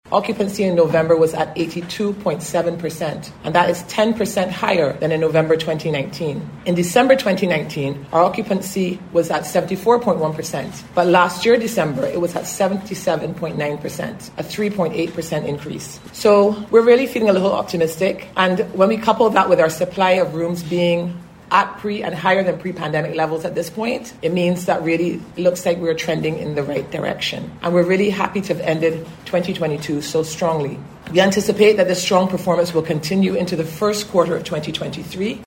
This was among the disclosures this morning during a BHTA news briefing.